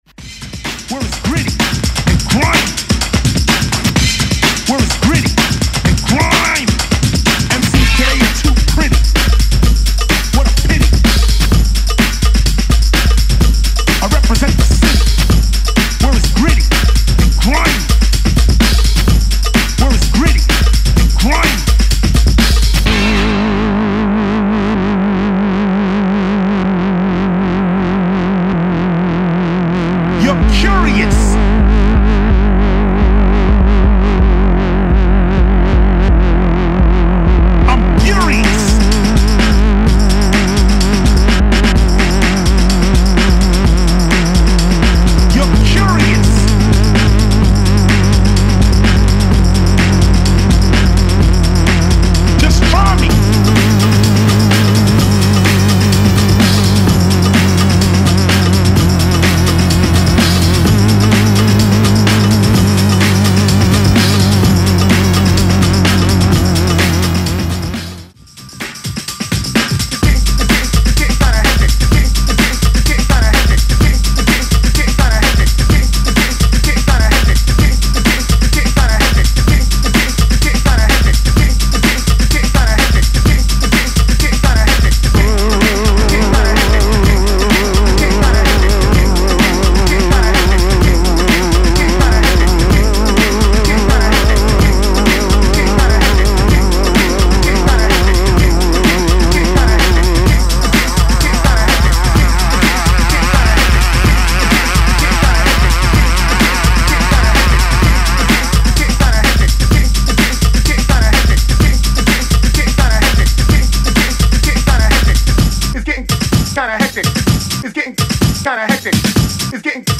BASS / BREAKS /DUB STEP / GRIME